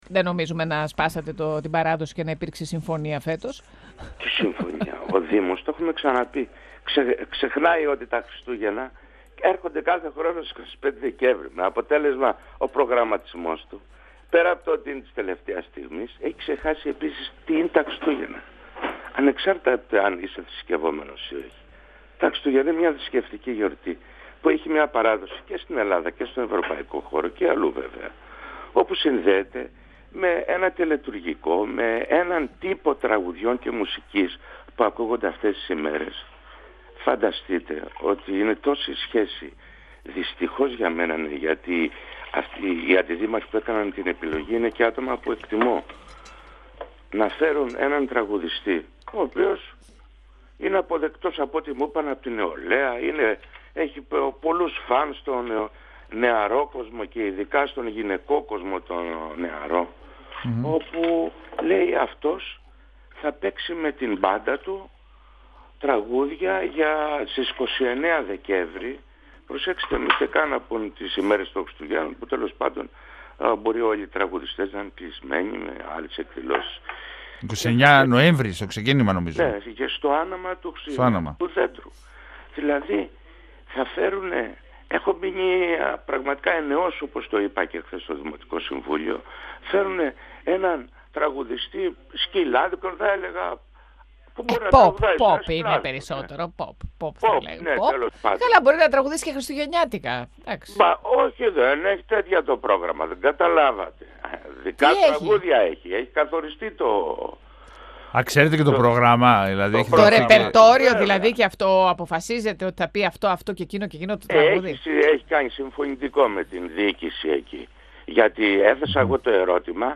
Έντονη κριτική στις επιλογές της διοίκησης για το πρόγραμμα των Χριστουγέννων άσκησε μιλώντας στον 102 φμ ο δημοτικός σύμβουλος Γιώργος Αβαρλής. Όπως είπε ο κ. Αβαρλής ο δήμος θα μπορούσε να επιλέξει πιο ποιοτικές λύσεις που να είναι και πιο κοντά στην παράδοση των γιορτών. Αναφέρθηκε επίσης στα προβλήματα που έχουν προκύψει με το νέο σύστημα στάθμευσης, λέγοντας ότι απασχολείται εκεί όλο το δυναμικό της δημοτικής αστυνομίας.
Αναφέρθηκε επίσης στα προβλήματα που έχουν προκύψει με το νέο σύστημα στάθμευσης, λέγοντας ότι απασχολείται εκεί όλο το δυναμικό της δημοτικής αστυνομίας. 102FM Συνεντεύξεις ΕΡΤ3